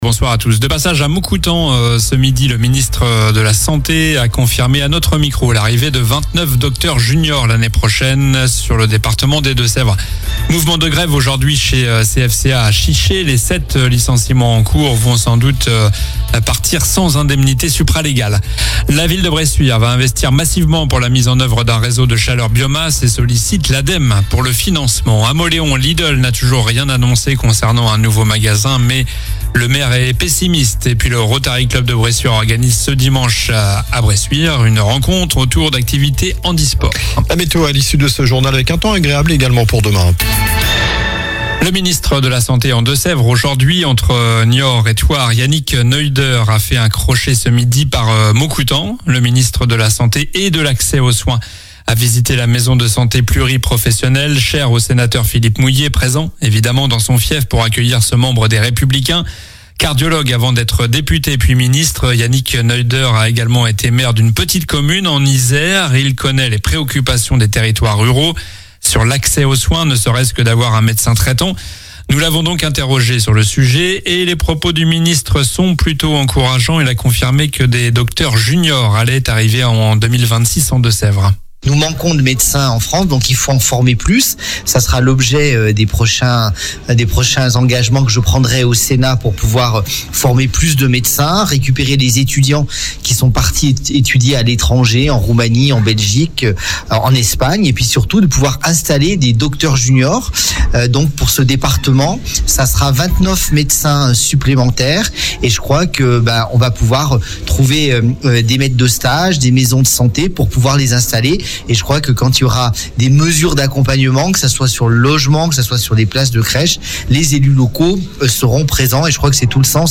Journal du jeudi 22 mai (soir)